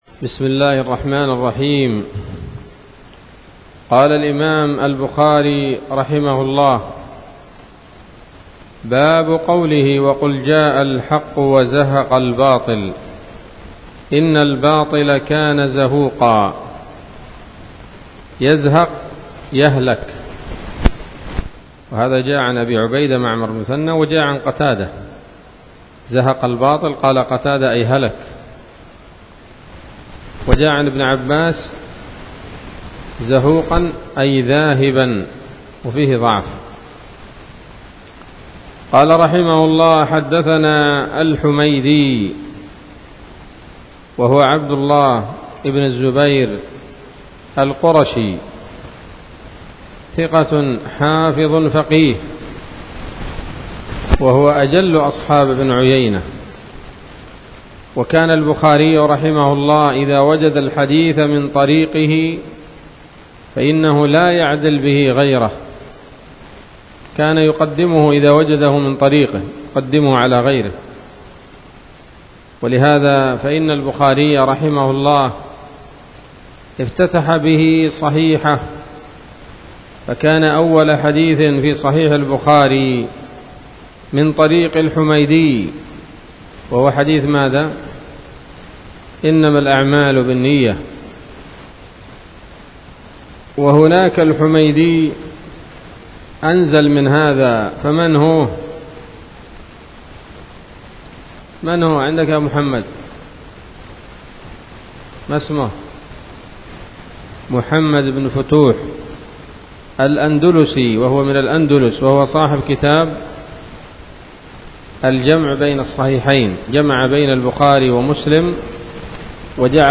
الدرس التاسع والخمسون بعد المائة من كتاب التفسير من صحيح الإمام البخاري